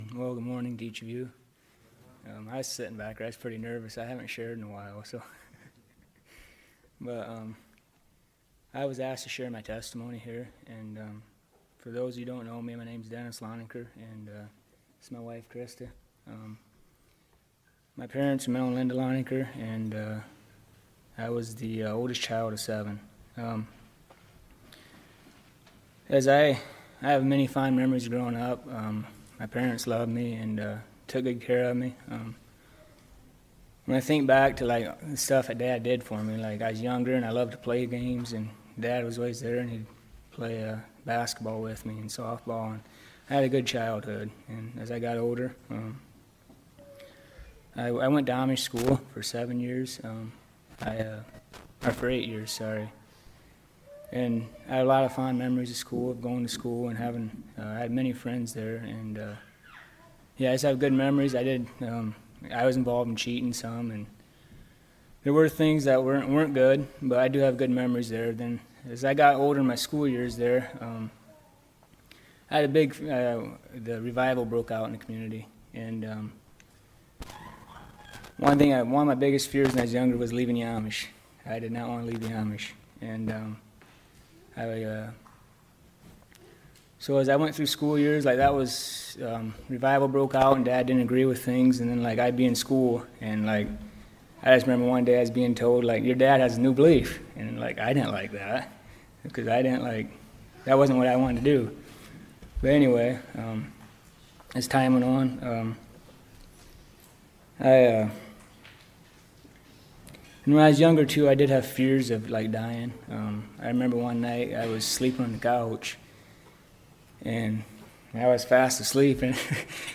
Personal Testimony